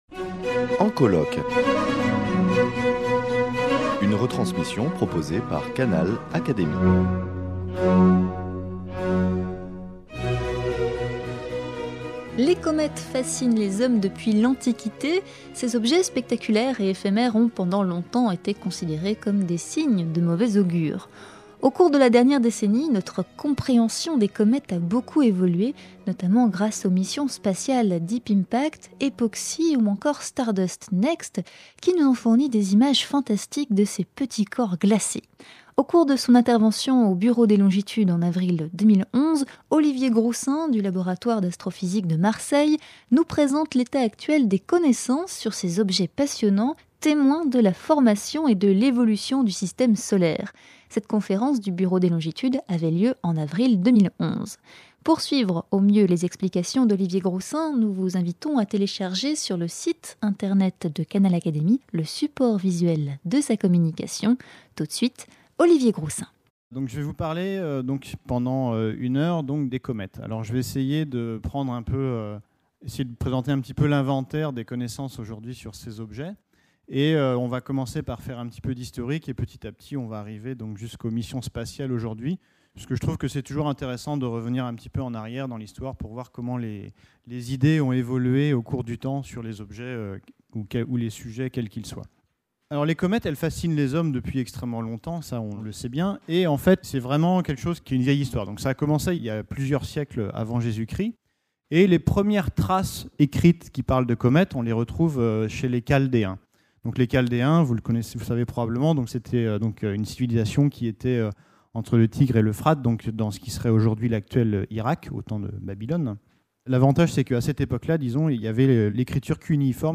Retransmission de la conférence du Bureau des longitudes d’avril 2011.